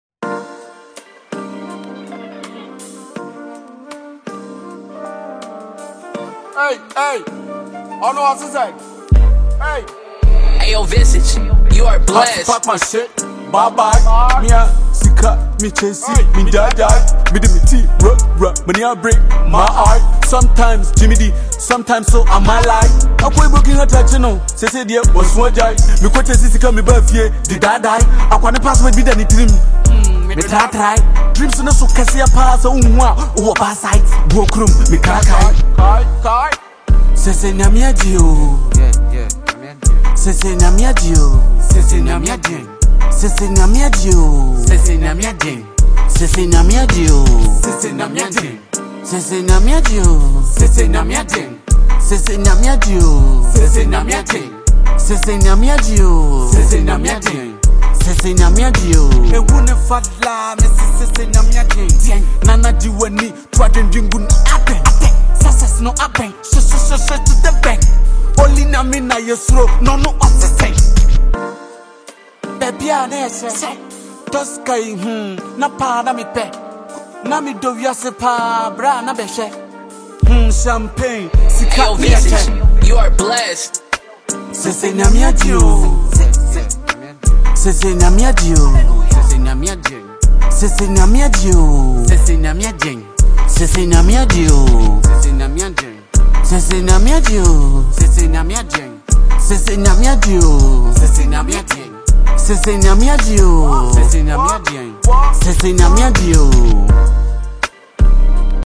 Ghanaian hip-hop record
Ghanaian rapper
Genre: Hip-Hop / Inspirational